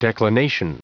Prononciation du mot declination en anglais (fichier audio)
Prononciation du mot : declination